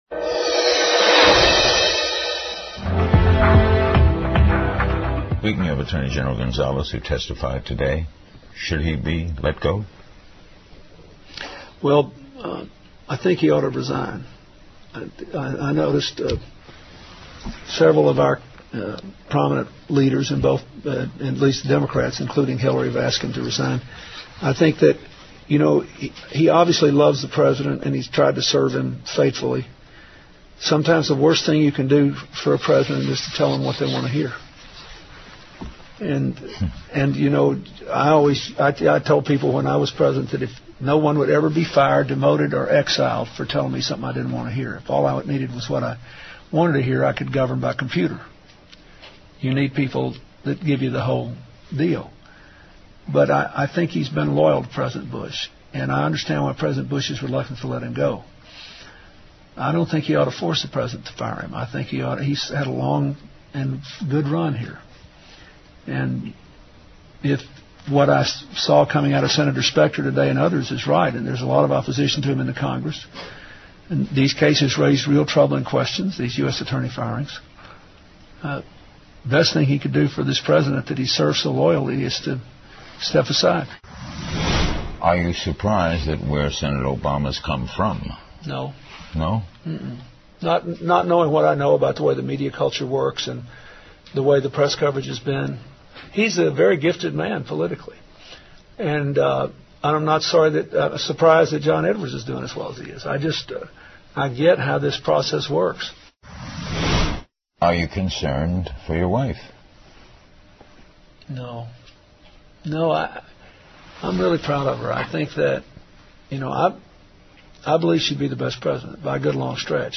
万花筒 Kaleidoscope 2007-04-20&22, CNN名嘴专访克林顿 听力文件下载—在线英语听力室